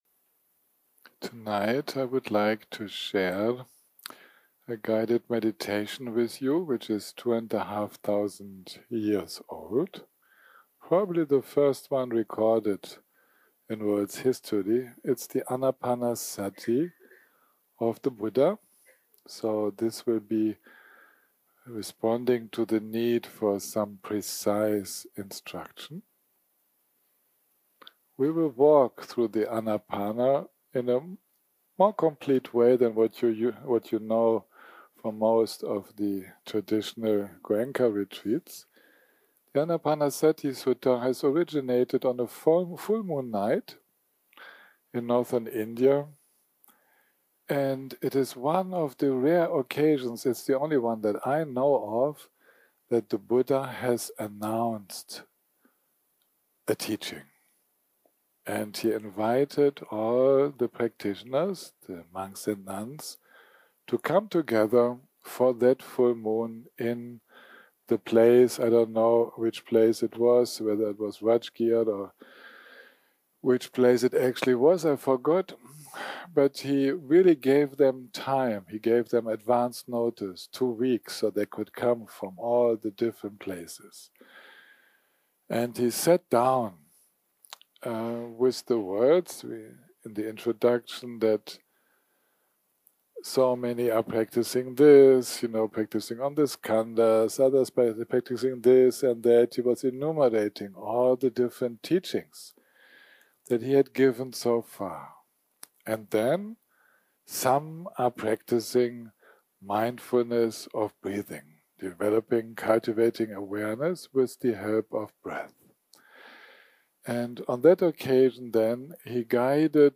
יום 2 - הקלטה 9 - ערב - מדיטציה מונחית - Anapanasati and chanting
מדיטציה מונחית